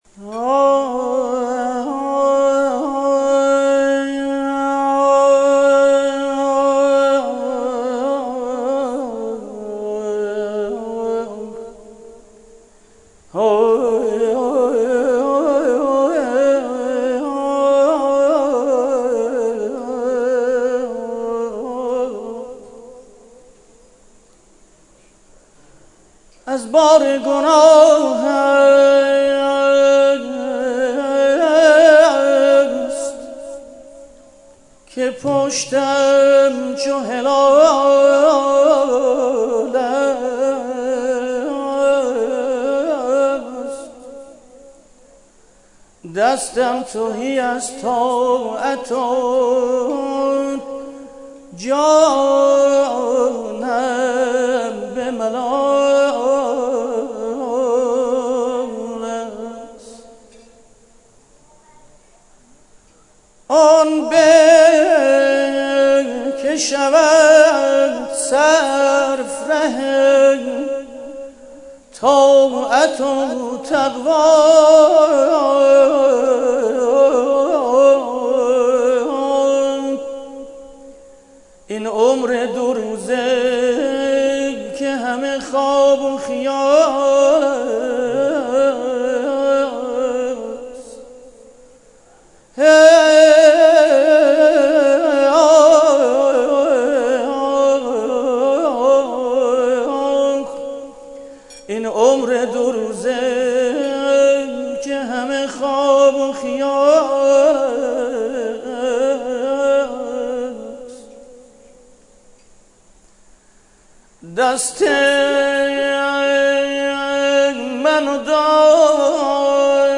شعرخوانی